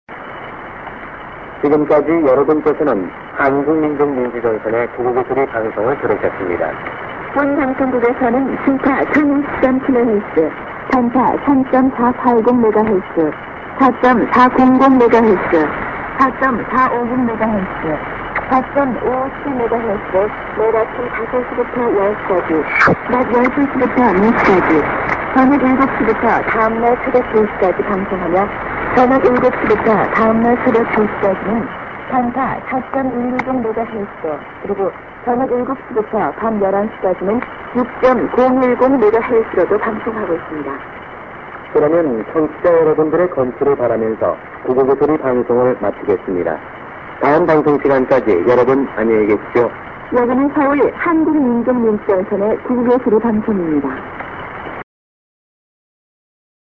b:　Korian ID+SKJ(man+women)->